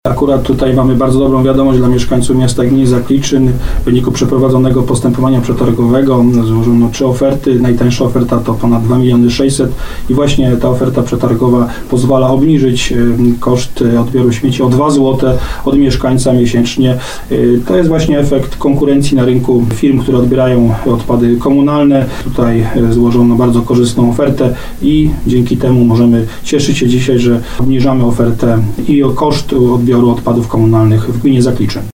Burmistrz Dawid Chrobak podkreśla, że obniżka stawek jest możliwa dzięki korzystnym wynikom ostatniego przetargu na odbiór i zagospodarowanie odpadów.